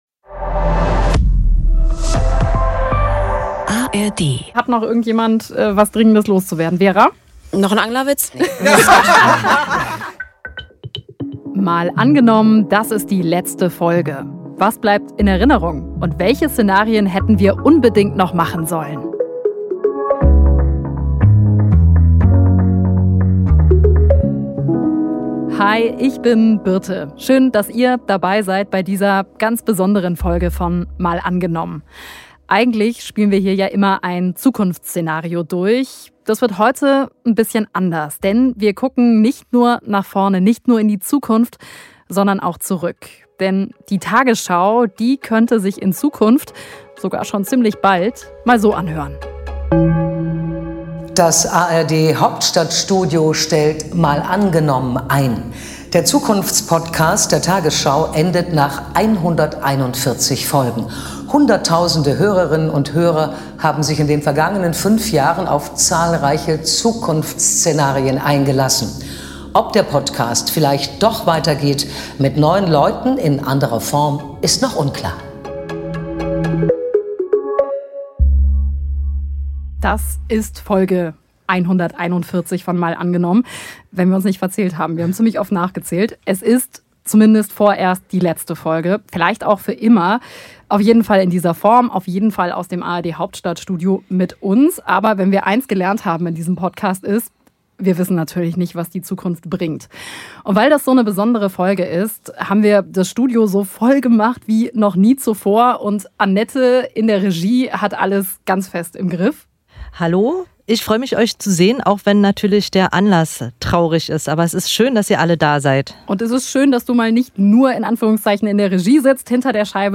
Was bleibt in Erinnerung? Zum Abschied blickt das ganze Team aus dem ARD-Hauptstadtstudio zusammen zurück: auf besondere Szenarien, tolle Folgen und lustige Rechercheerlebnisse.